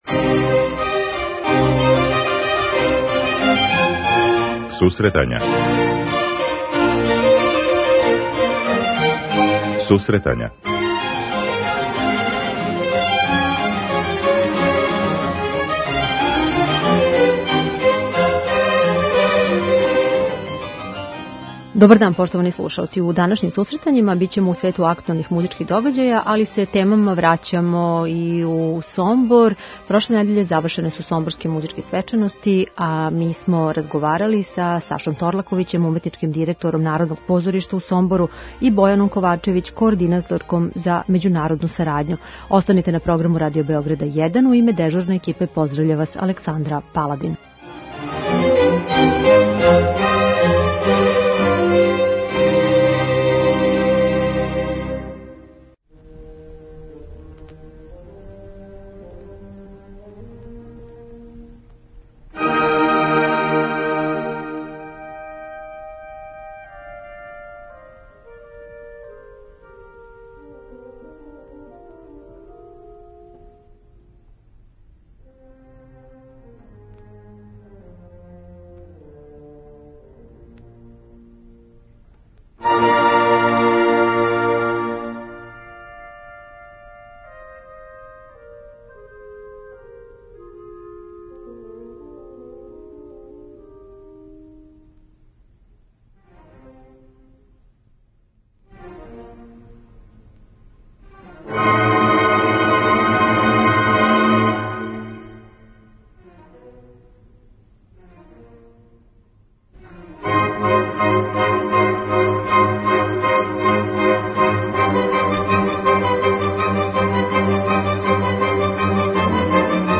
Чућете разговор